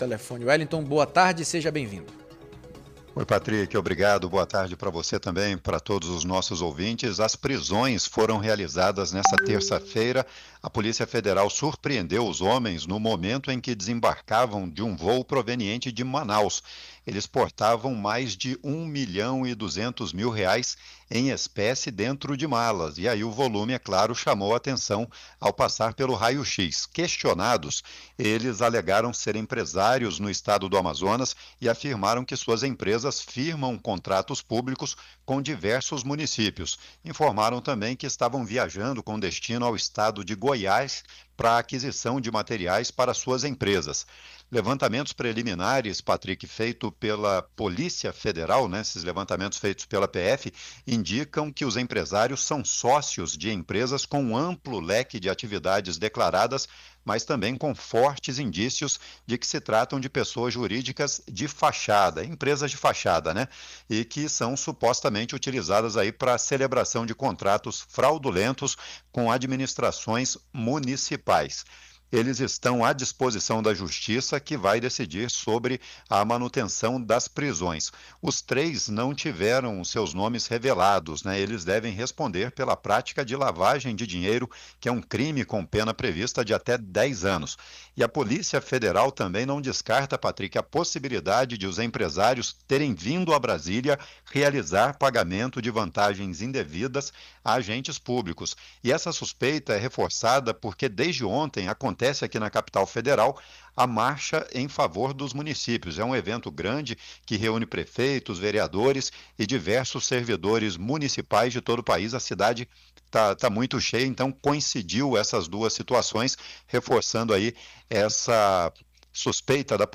AO VIVO DE BRASÍLIA